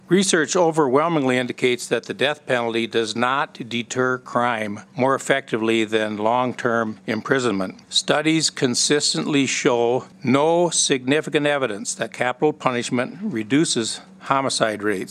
Representative Tim Reisch of Howard introduced the bill